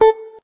note_beepy_6.ogg